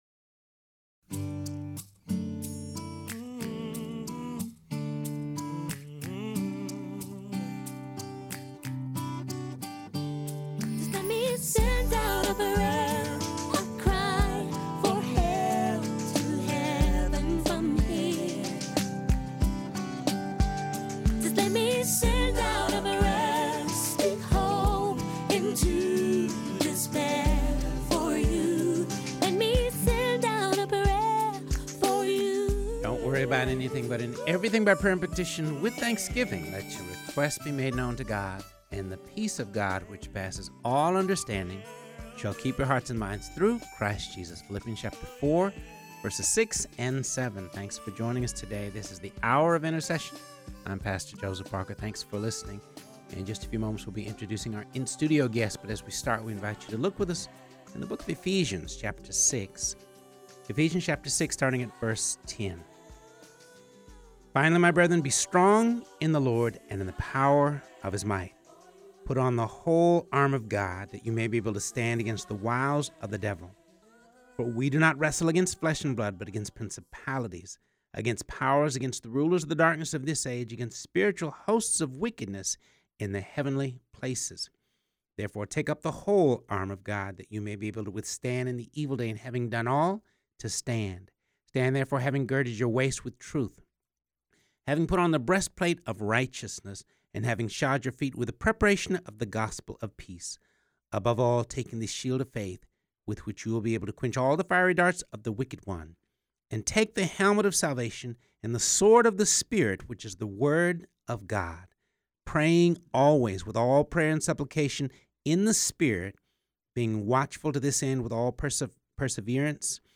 in studio to talk about a school prayer walk event she is helping to coordinate.